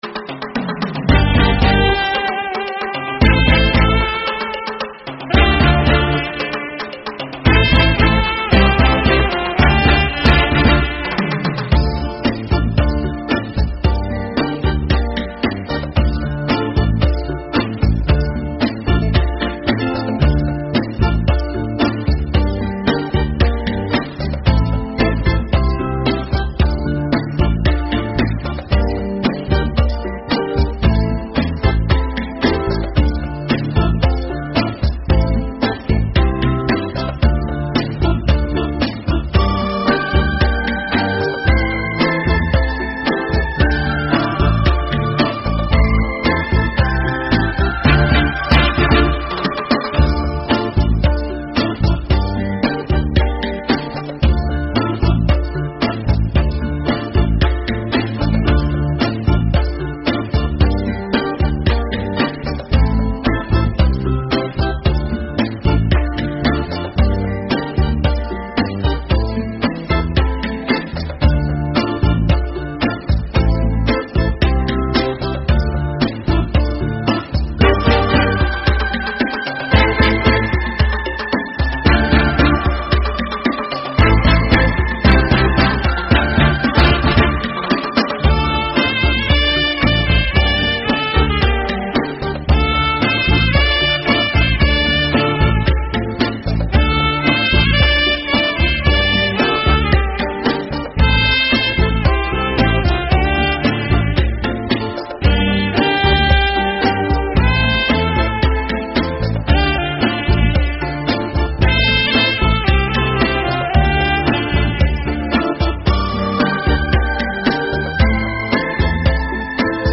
无 调式 : 降B 曲类